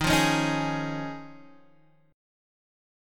D#7sus2sus4 chord